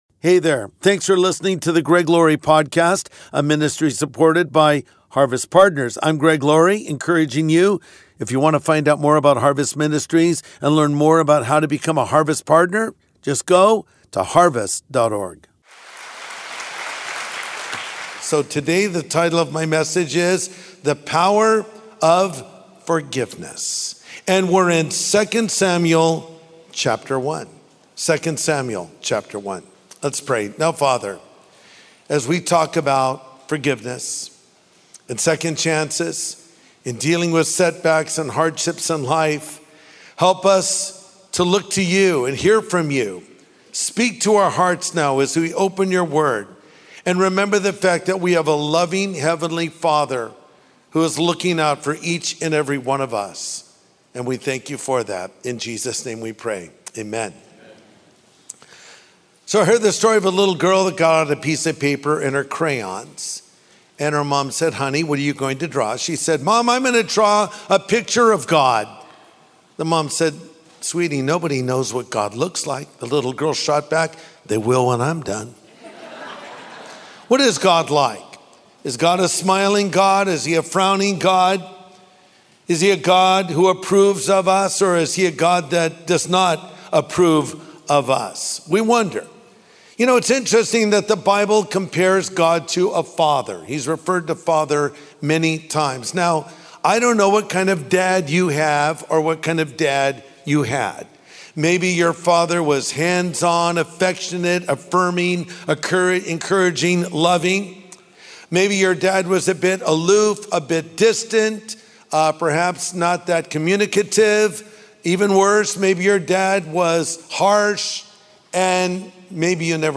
Pastor Greg Laurie brings us a message on the power of forgiveness, a virtue every Christian should embrace.